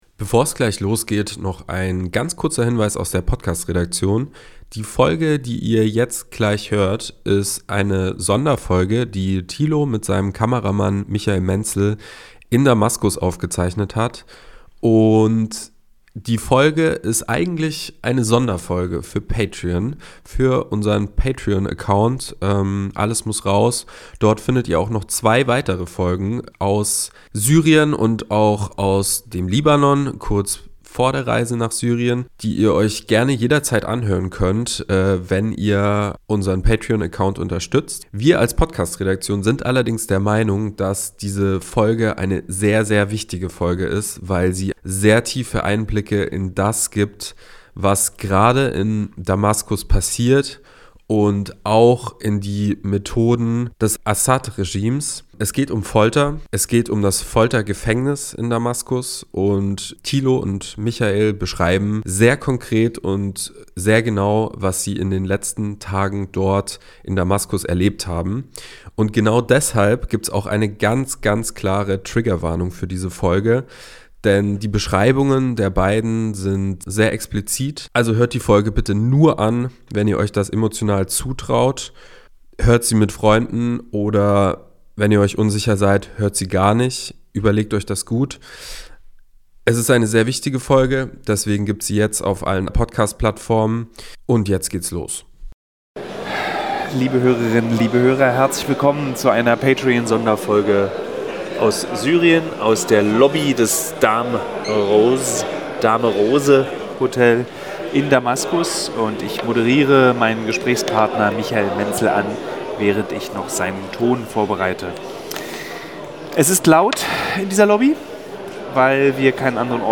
Direkt aus der Lobby des Dame Rose Hotels in Damaskus
Ein intensives 30-minütiges Gespräch